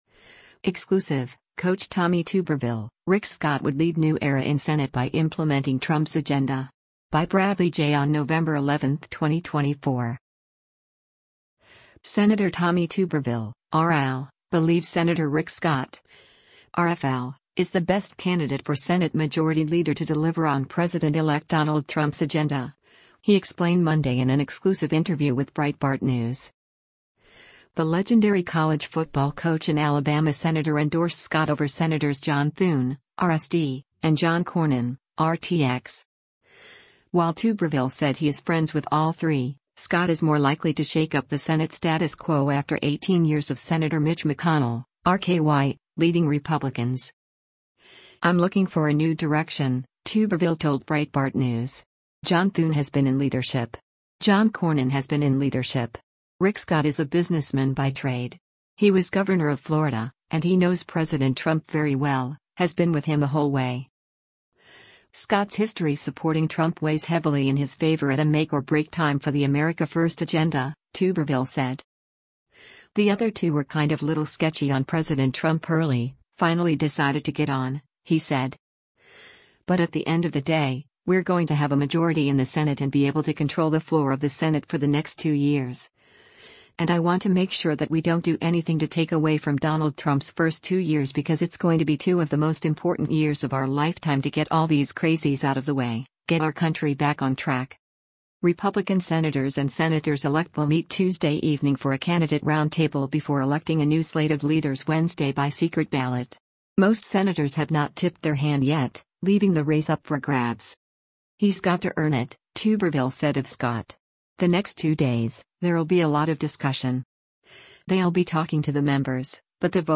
Sen. Tommy Tuberville (R-AL) believes Sen. Rick Scott (R-FL) is the best candidate for Senate Majority Leader to deliver on President-elect Donald Trump’s agenda, he explained Monday in an exclusive interview with Breitbart News.